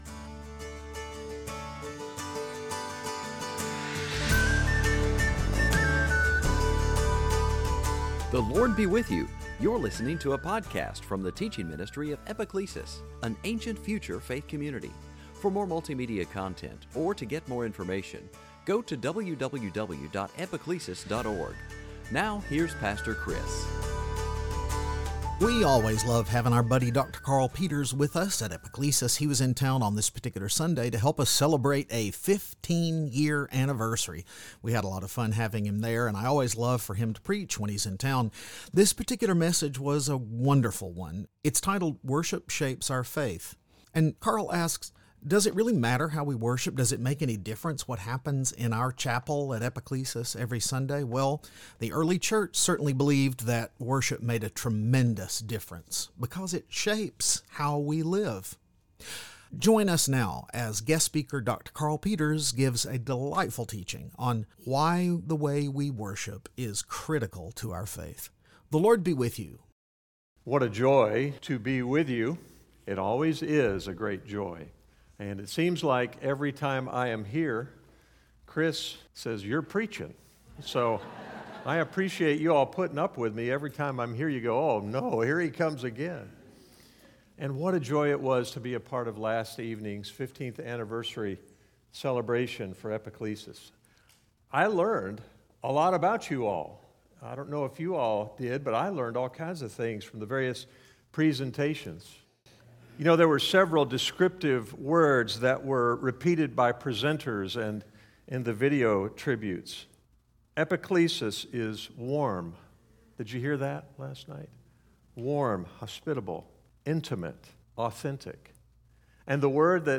Sunday Teaching